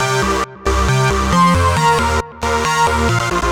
Index of /musicradar/future-rave-samples/136bpm